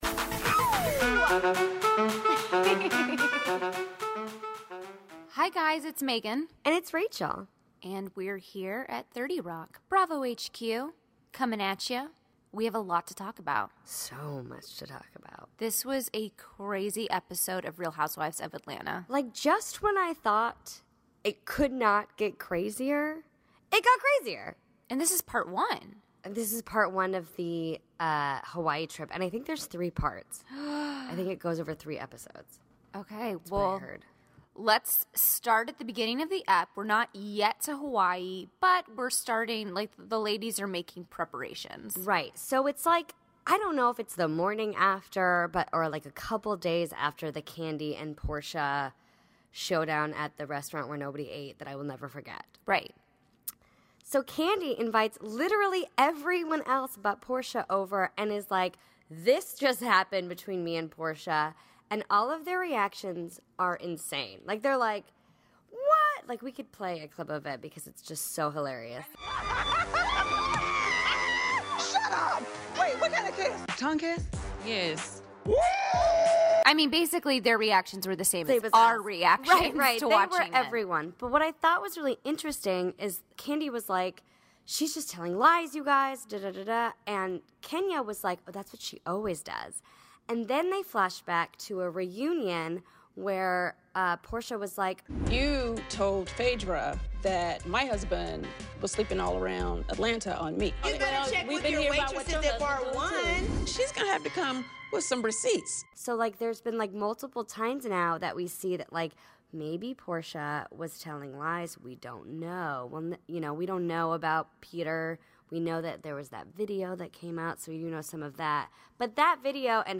Monday, March 6, 2017 - From Bravo HQ in New York City, we're unpacking part 1 of The Real Housewives of Atlanta's trip to Hawaii. We spoke to Kandi Burress about her rocky plane ride on the way there and dive into her epic screenshot reveal.